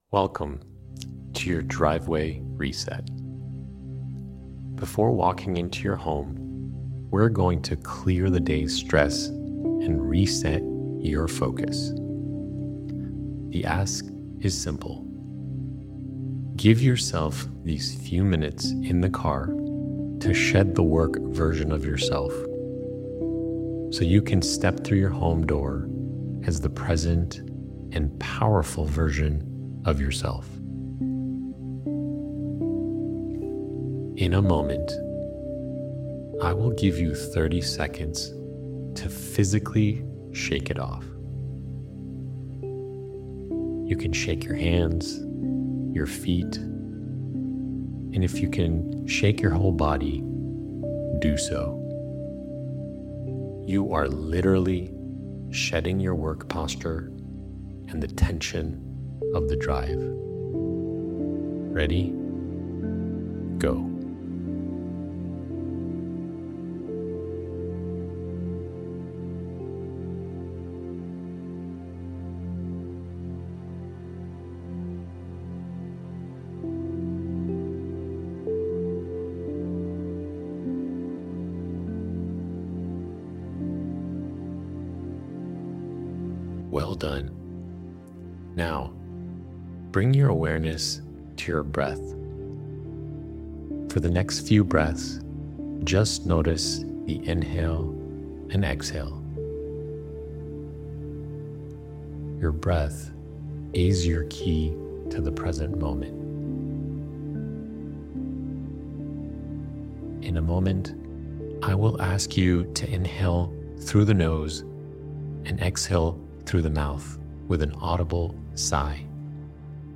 The Driveway Reset: A Guided Meditation for Men to Leave Work Behind and Show Up Fully at Home | Inner Edge Blog
The practice is fully guided and designed specifically for men who may be new to breathwork and mindfulness.